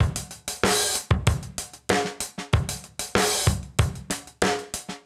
SSF_DrumsProc2_95-04.wav